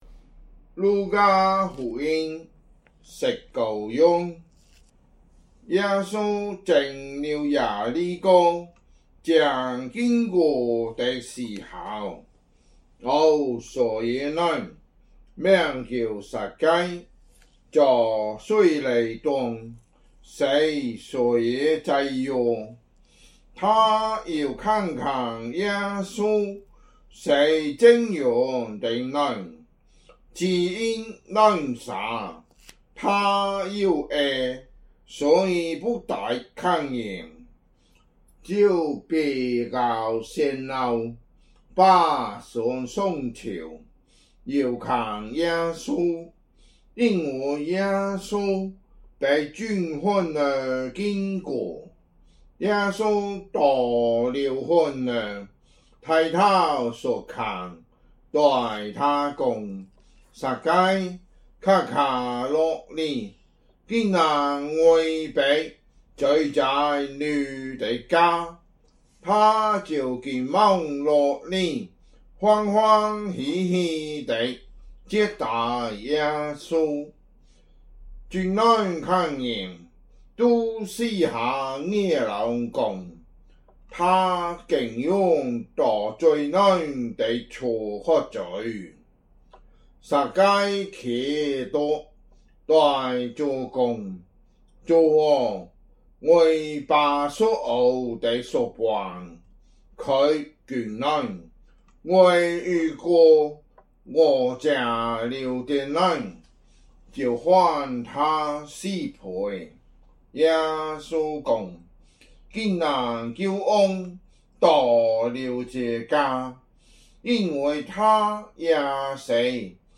福州話有聲聖經 路加福音 19章